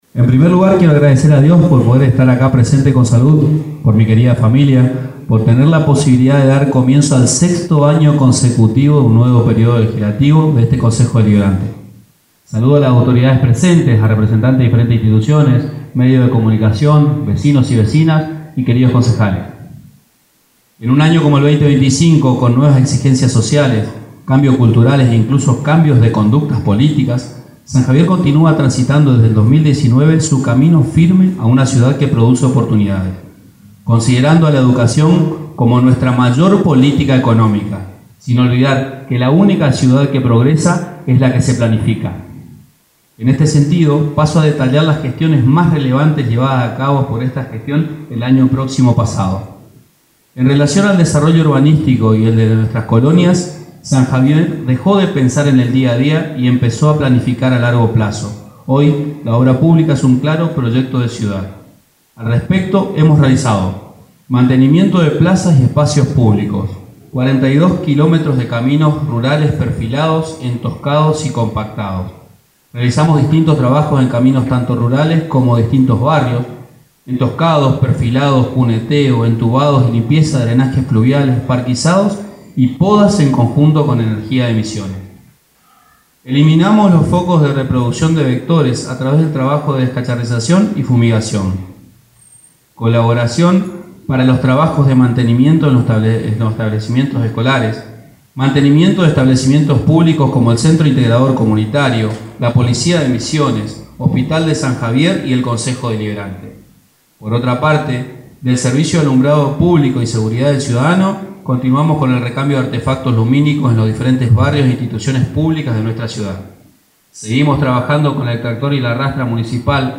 El intendente de San Javier, Matías Vilchez, dejó inaugurado el período legislativo 2026 en la sala de sesiones del Concejo Deliberante, marcando el inicio de su sexto año consecutivo al frente del Ejecutivo municipal. En un discurso centrado en la planificación, la educación y el desarrollo local, el jefe comunal repasó los principales logros de su gestión y delineó los desafíos para el año en curso.
DISCURSO-DR-MATIAS-VILCHEZ.mp3